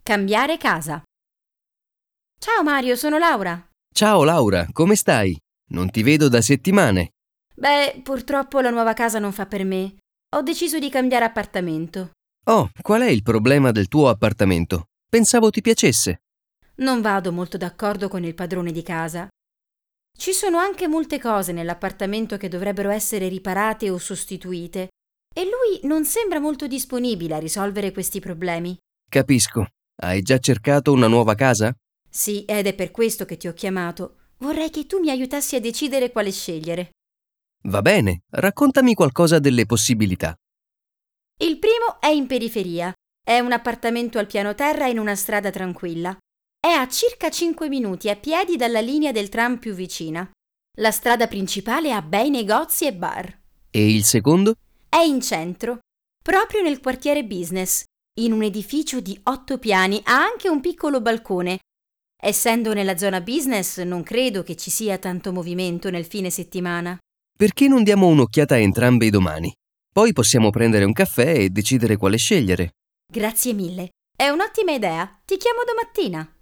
hasznos párbeszédek audióval